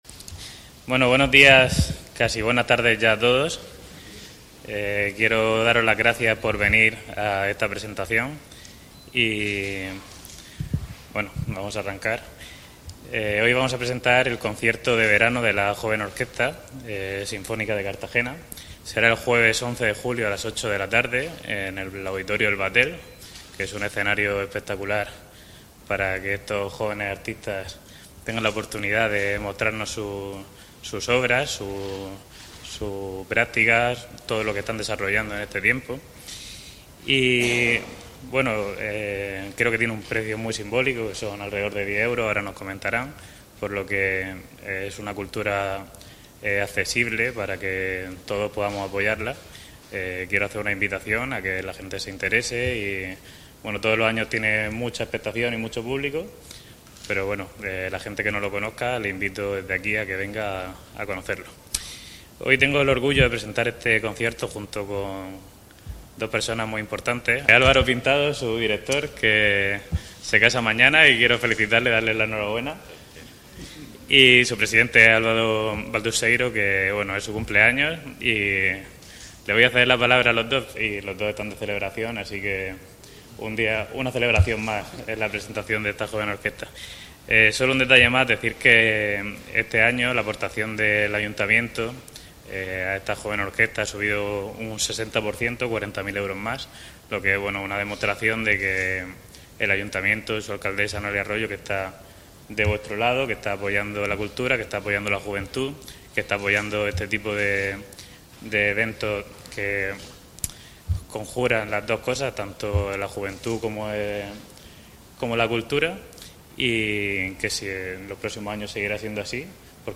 Presentación del concierto de verano de la JOSCT